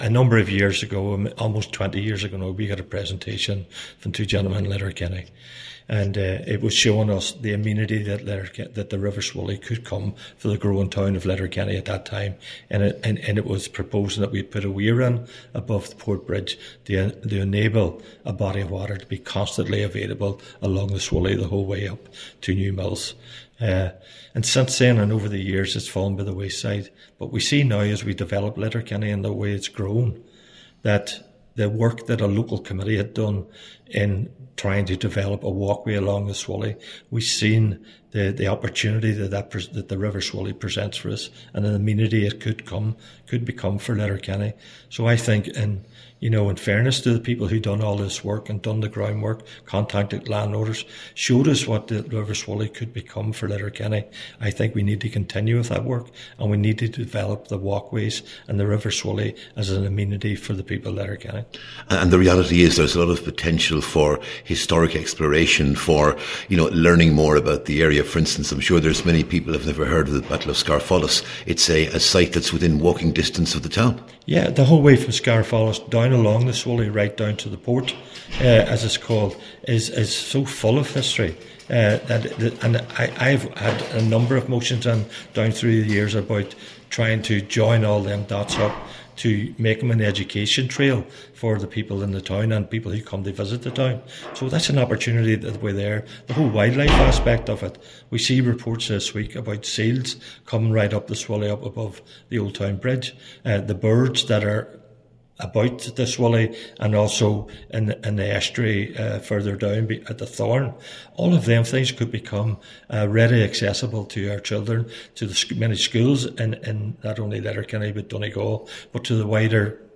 Cllr McMonagle says the river can become a central feature of Letterkenny, but that will not happen without effort: